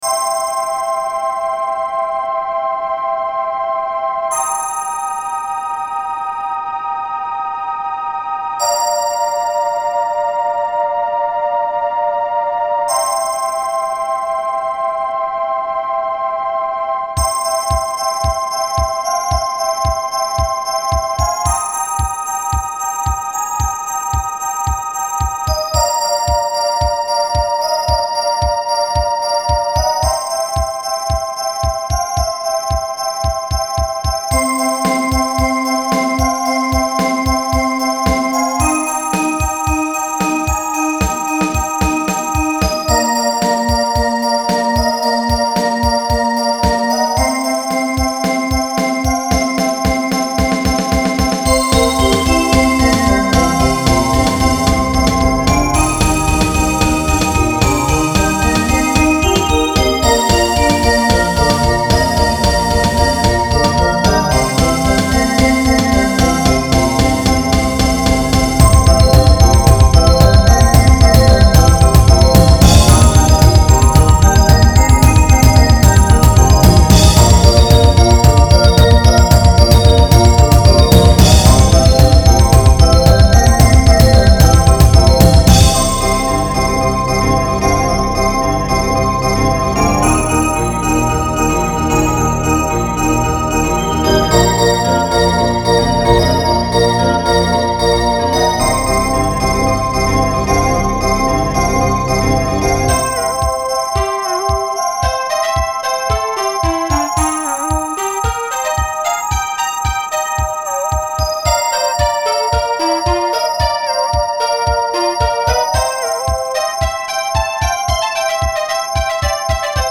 I am reposting some of my early MIDI files with changes in stereo. This one could be a kid's march.